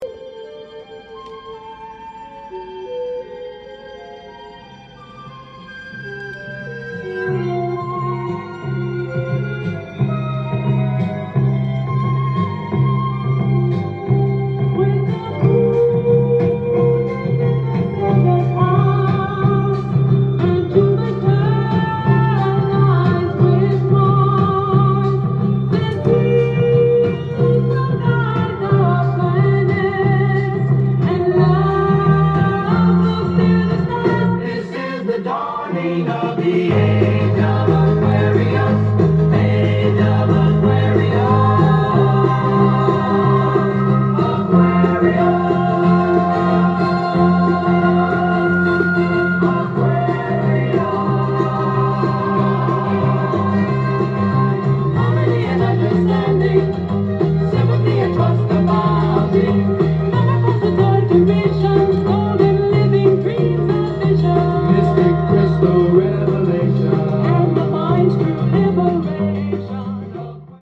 ジャンル：Soul-7inch-全商品250円
店頭で録音した音源の為、多少の外部音や音質の悪さはございますが、サンプルとしてご視聴ください。